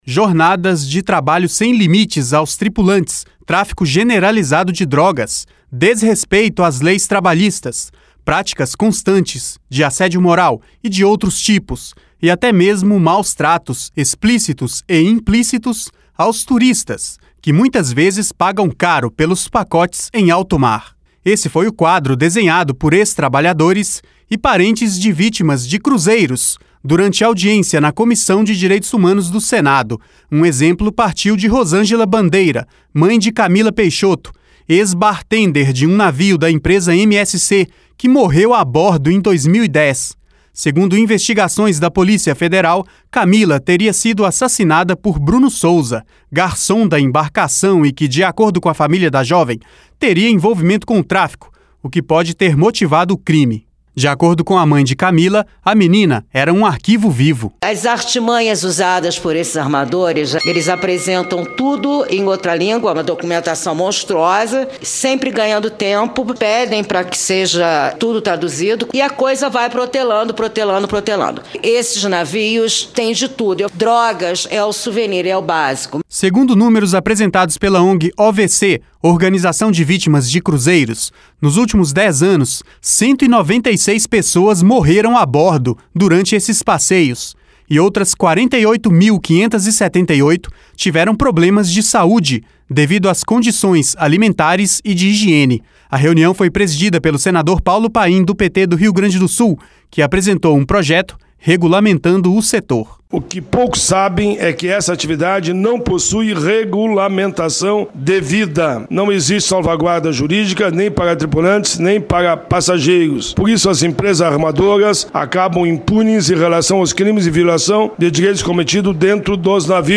Este foi o quadro desenhado por ex-trabalhadores e parentes de vítimas de cruzeiros durante audiência na Comissão de Direitos Humanos do Senado.